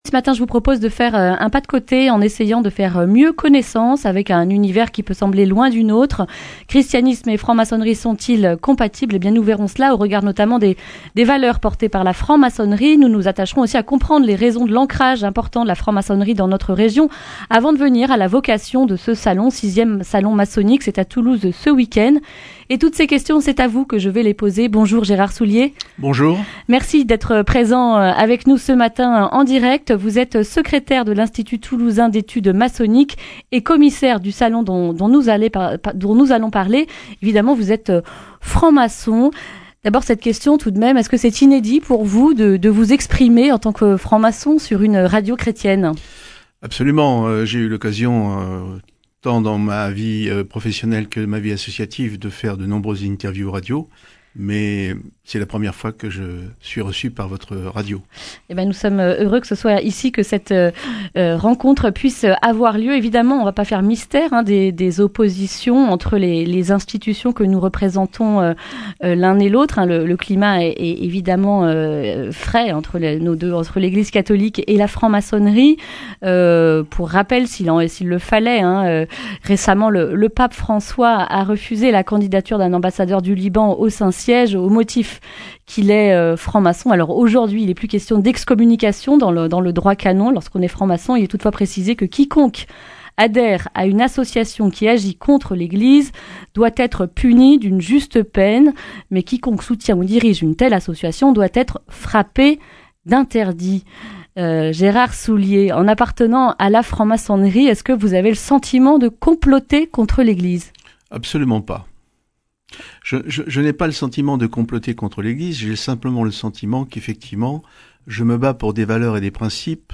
Le grand entretien